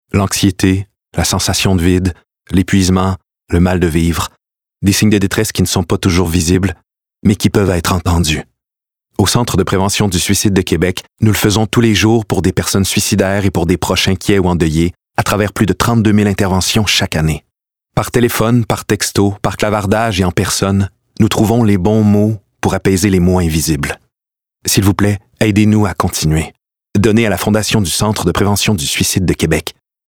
Voix annonceur – CPSQ
sérieuse, émotion